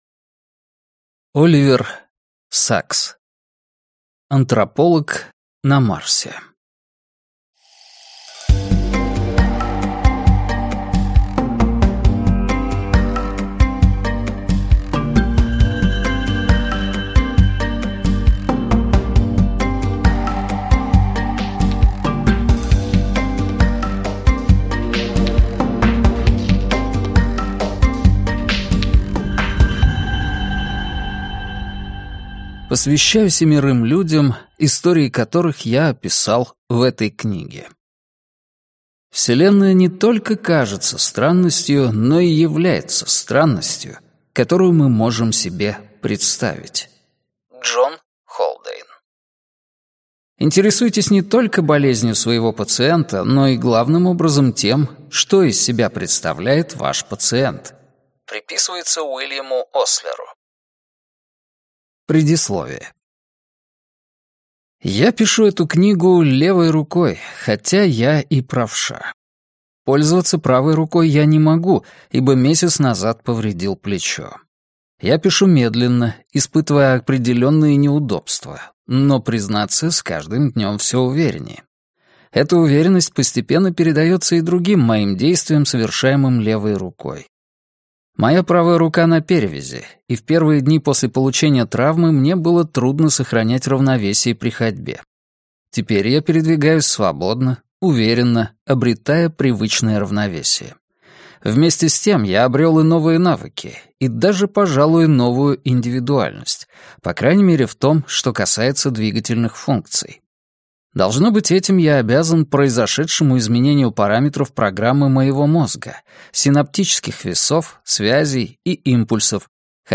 Аудиокнига Антрополог на Марсе - купить, скачать и слушать онлайн | КнигоПоиск